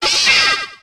Cri de Berserkatt dans Pokémon HOME.